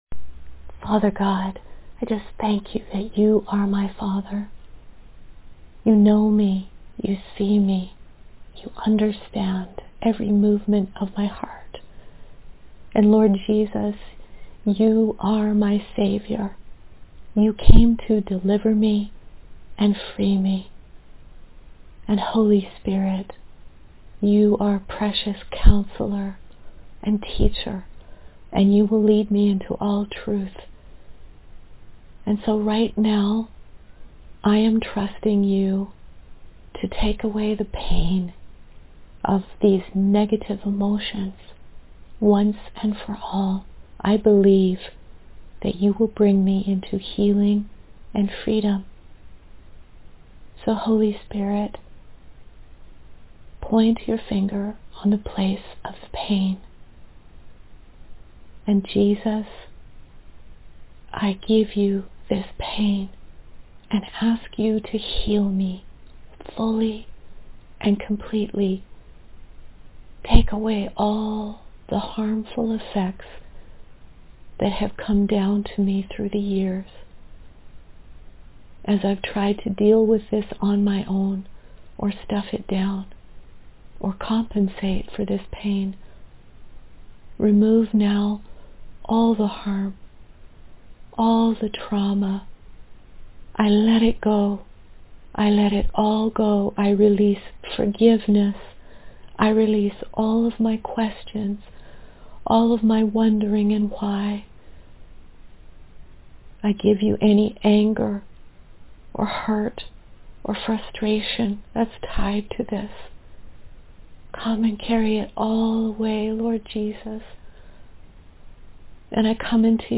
A Prayer for Emotional Healing
Prayer-for-emotional-Healing.mp3